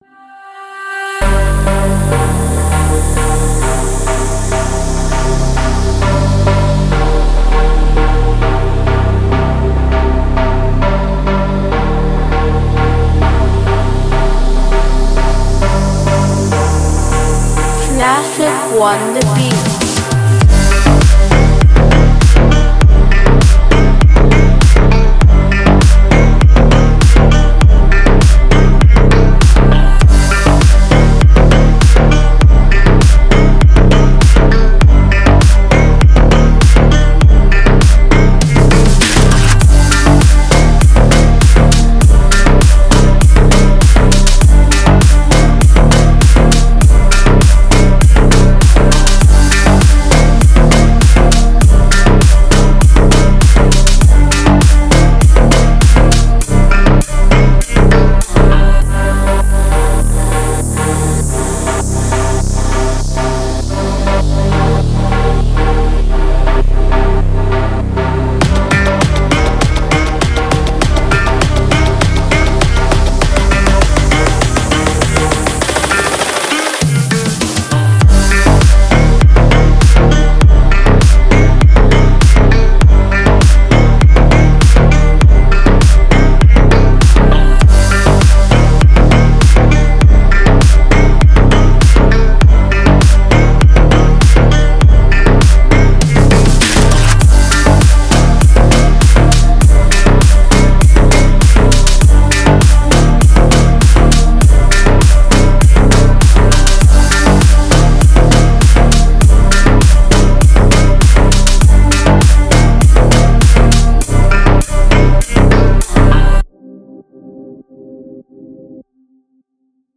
Категория: Клубная музыка
клубные треки